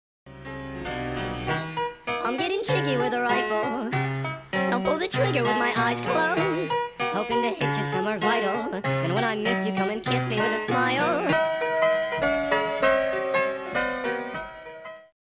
sped up ver.